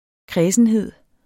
Udtale [ ˈkʁεːsənˌheðˀ ]